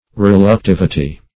(physics) the resistance of a material to the establishment of a magnetic field in it ; The Collaborative International Dictionary of English v.0.48: Reluctivity \Rel`uc*tiv"i*ty\, n. (Elec.)